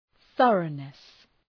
Προφορά
{‘ɵʌrənıs}